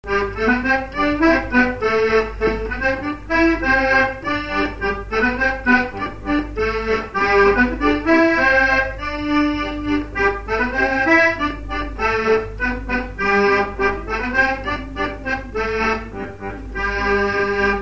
Résumé instrumental
gestuel : à marcher
circonstance : fiançaille, noce
Pièce musicale inédite